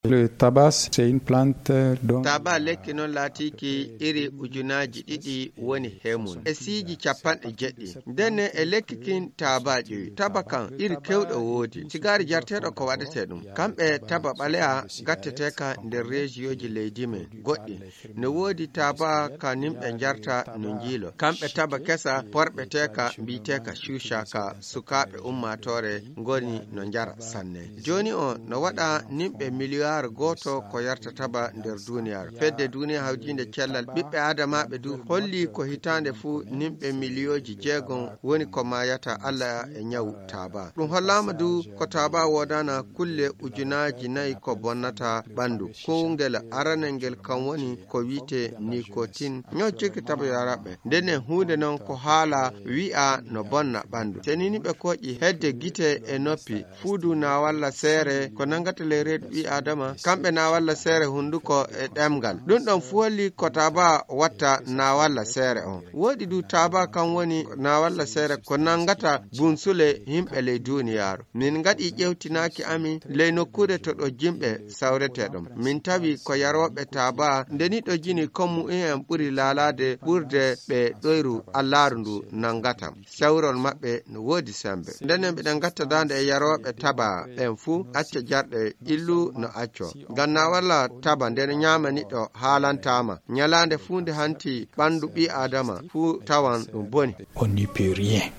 Magazine en fulfuldé